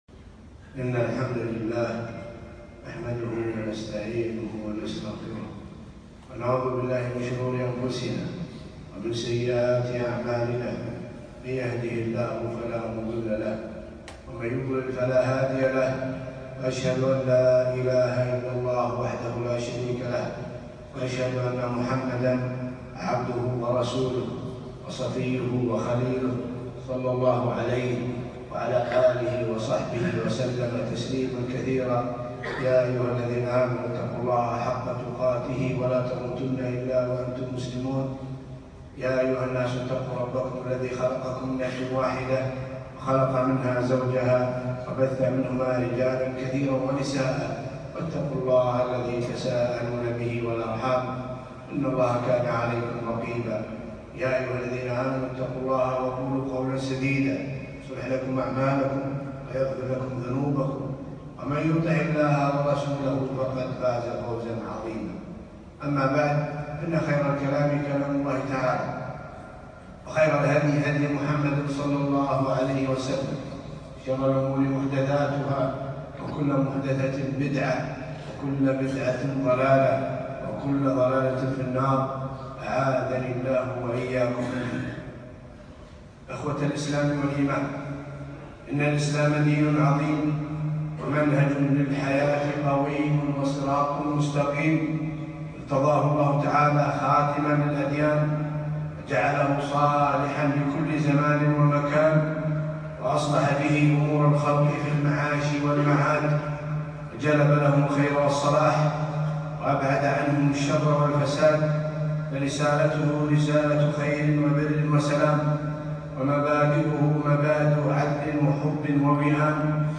خطبة - خلق الرفق